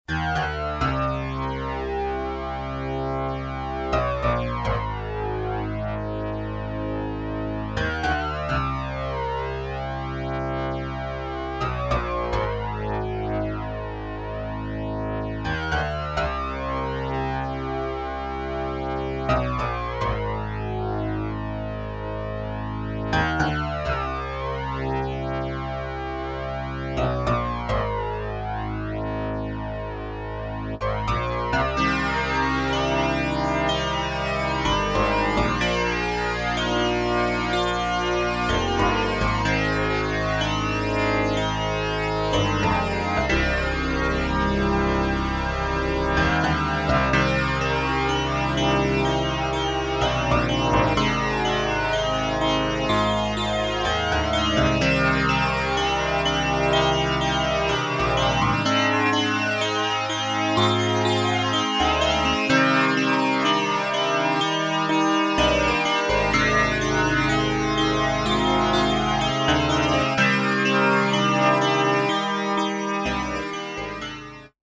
Elka Synthex
Hardsynch: 2>1 demo Hear
hardsync harp